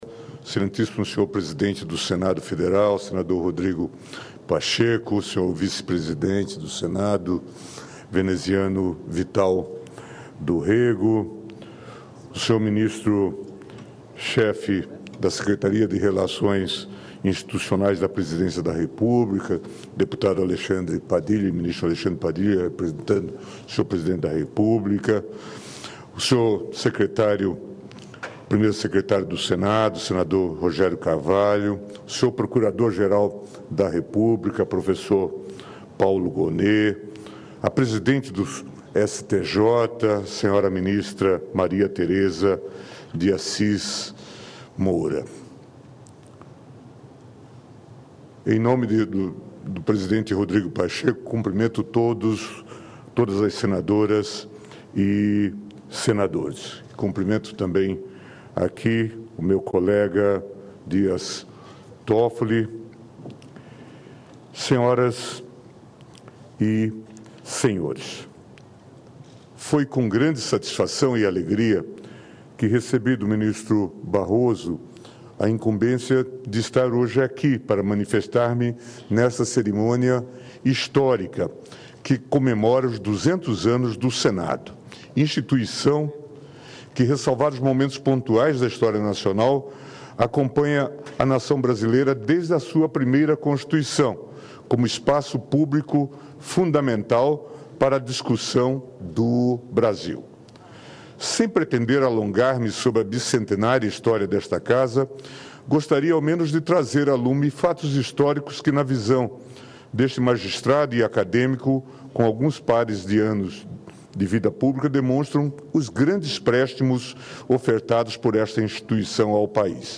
Pronunciamento do ministro do Supremo Tribunal Federal (STF) Gilmar Mendes, representando o Poder Judiciário na sessão especial em comemoração aos 200 anos do Senado brasileiro.